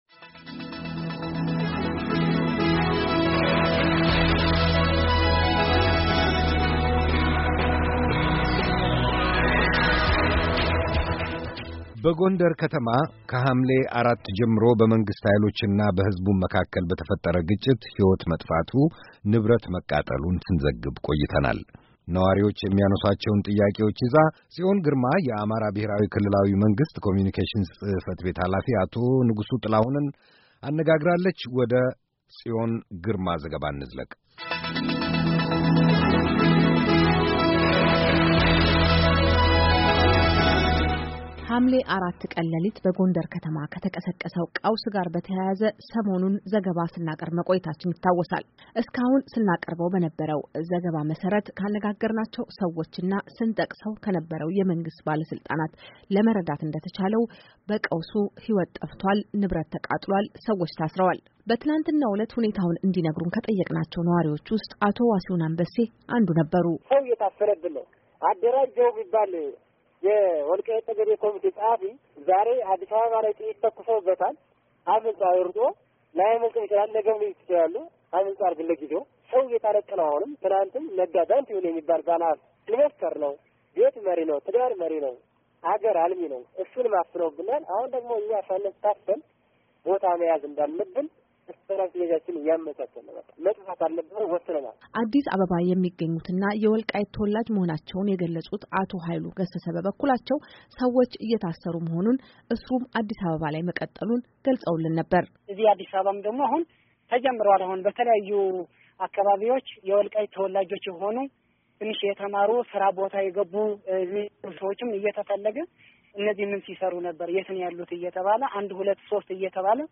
ከአማራ ክልል ኮምዩኒኬሽን ጽ/ቤት ኃላፊ አቶ ንጉሱ ጥላሁን ጋር የተደረገ ቃለ ምልልስ
የወልቃይት የአማራ ሕዝብ ማንነት ጥያቄን ለማዳፈን ሰዎች ከያሉበት እየታሠሩ እንደሆነ የአሜሪካ ድምጽ ያነጋገራቸው አስተያየት ሰጪዎ ይናገራሉ። በሰሞኑ ጉዳይ ላይ የአማራ ብሔራዊ ክልላዊ መንግሥት ኮምዩኒኬሽን ጽ/ቤት ኃላፊ አቶ ንጉሱ ጥላሁን በትናንትናው ዕለት ቃለ ምልልስ ሰጥተዋል።